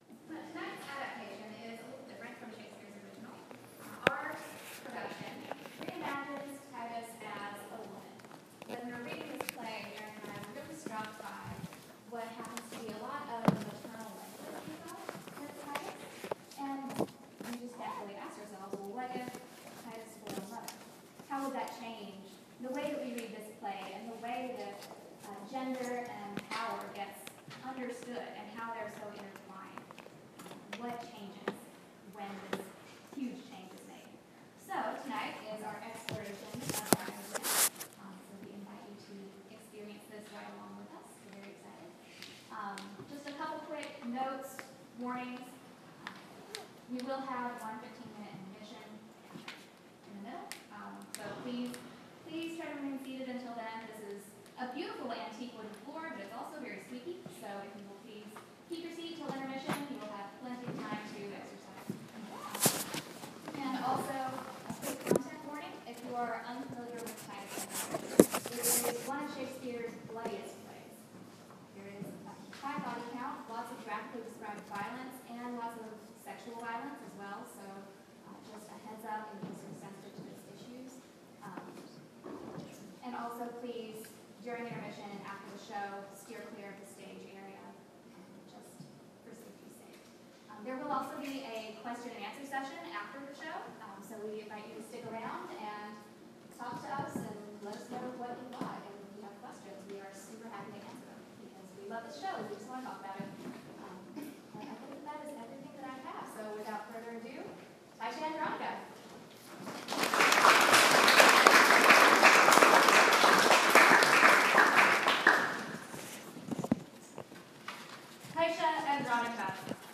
Apologies for the background noise at the start. It dies down once the performance begins.